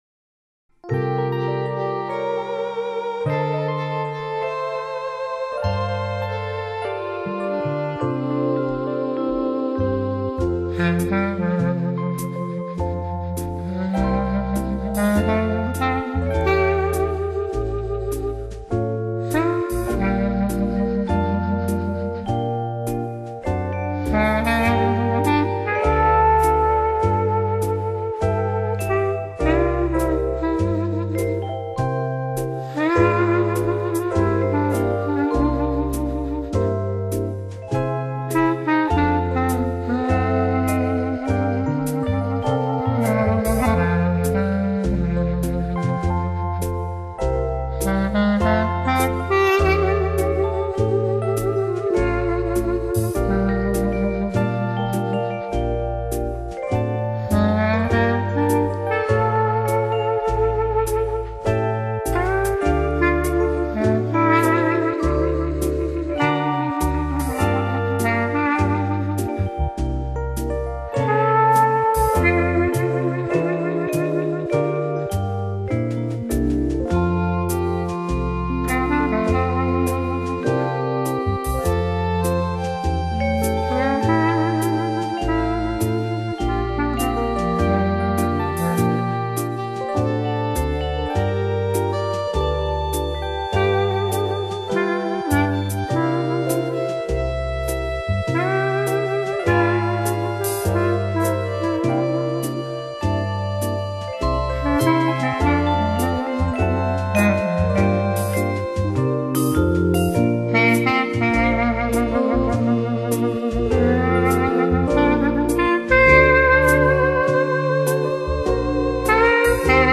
【单簧管专辑】